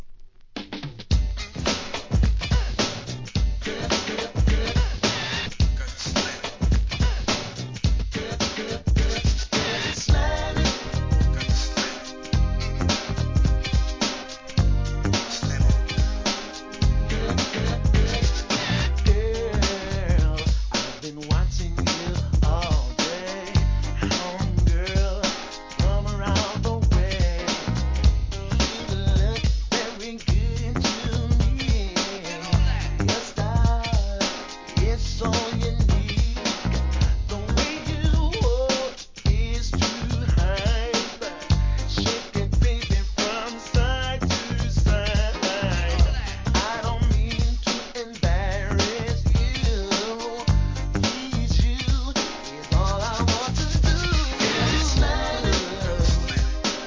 HIP HOP/R&B
NEW JACK SWING!!